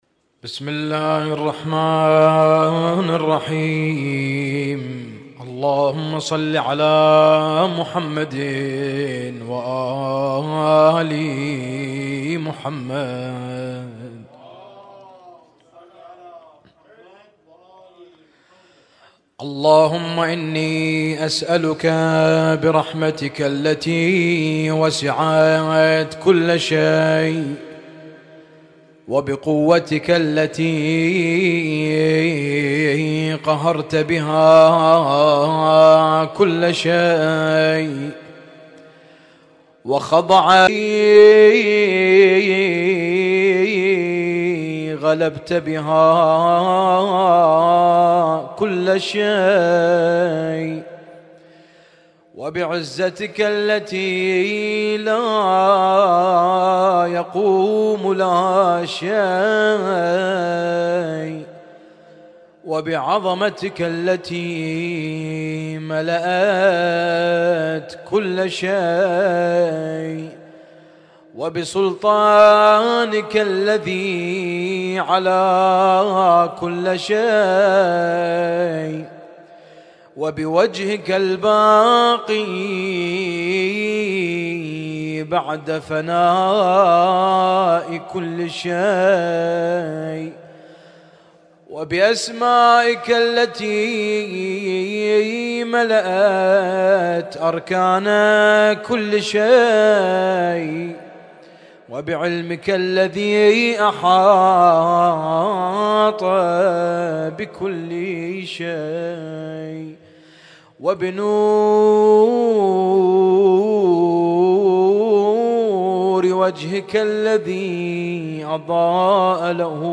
اسم التصنيف: المـكتبة الصــوتيه >> الادعية >> دعاء كميل